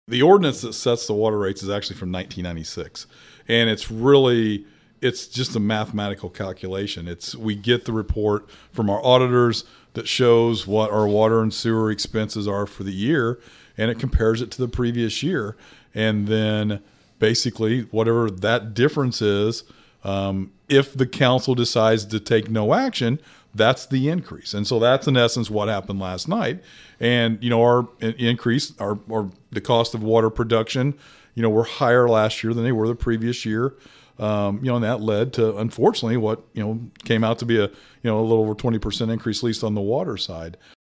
Speaking on our podcast “Talking about Vandalia,” Mayor Doug Knebel says this is how the nearly 30 year old ordinance is designed to work.